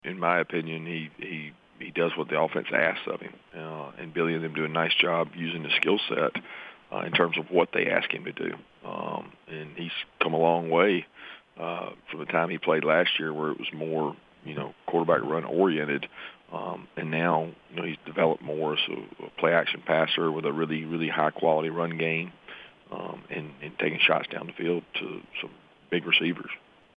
Georgia coach Kirby Smart spoke on the impact Richardson is making this season for the Gators.